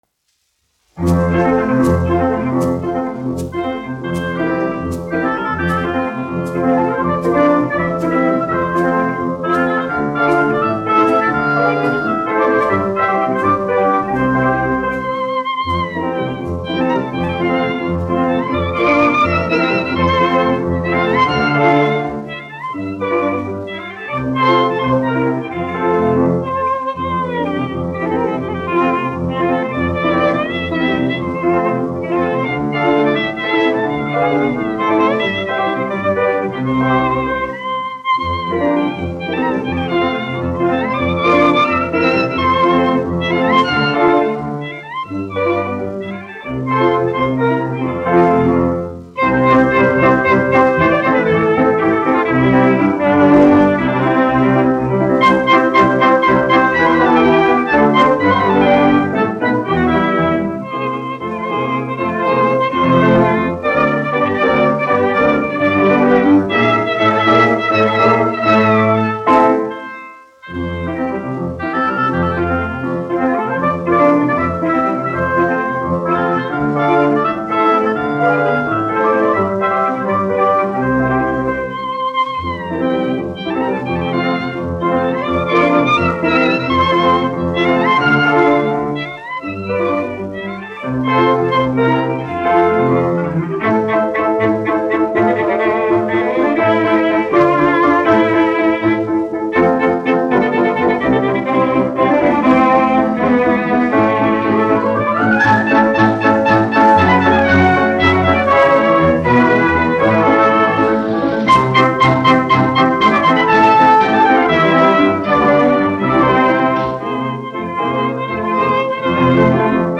1 skpl. : analogs, 78 apgr/min, mono ; 25 cm
Orķestra mūzika
Latvijas vēsturiskie šellaka skaņuplašu ieraksti (Kolekcija)